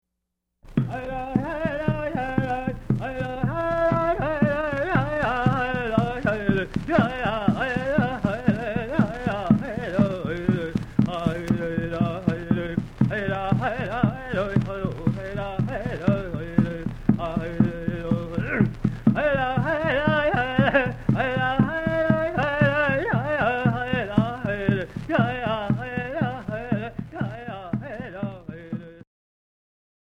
This song symbolizes two opposing parties out on the warpath, which is dramatized in the play of the hand game. The words sung here are vocables.